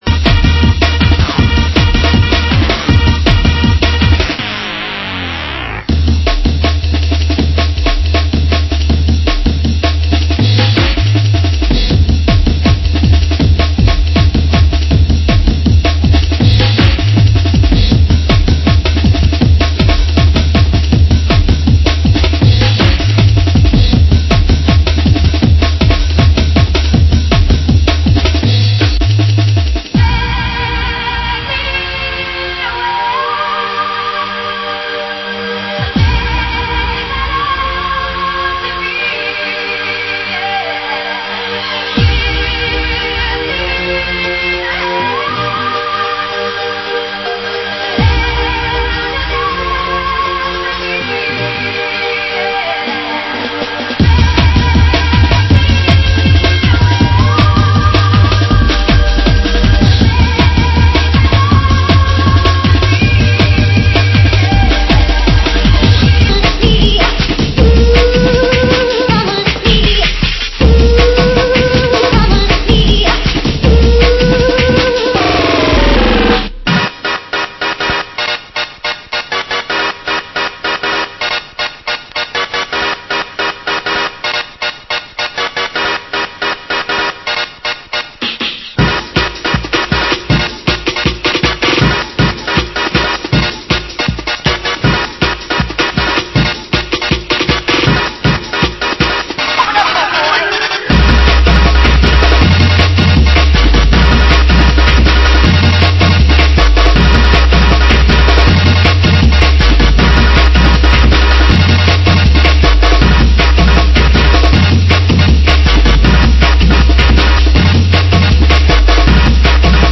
Genre: Happy Hardcore